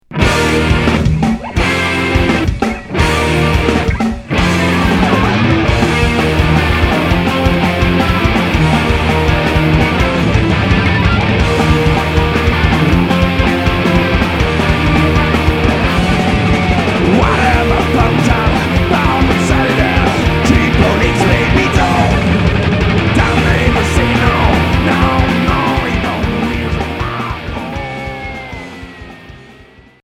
Hardcore émo Premier 45t